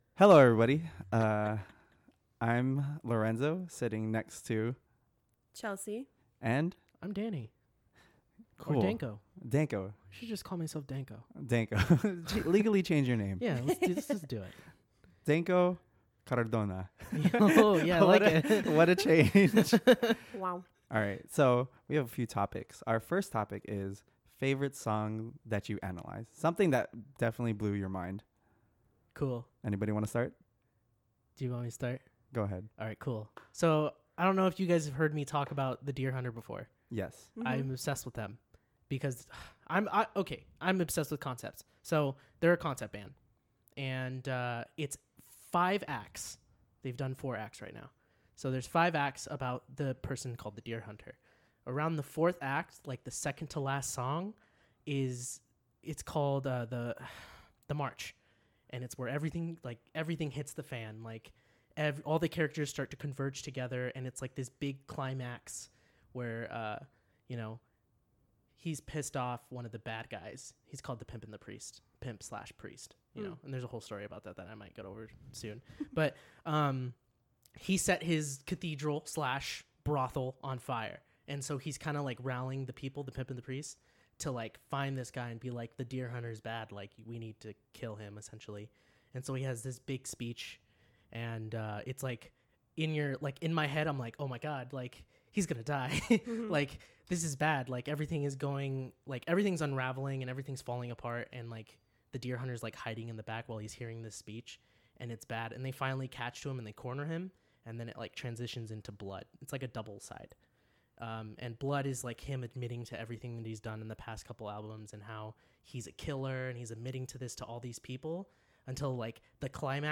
Everyone goes around the room and talks about the significance of certain songs and albums while also discussing what their favorite album of right now is !